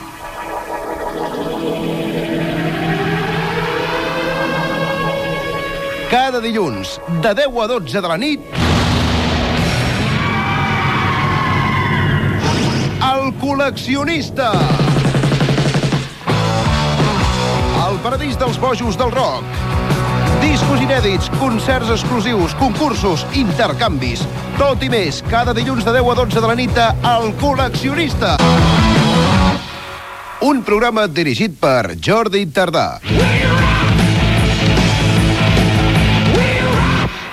Promoció del programa.
Musical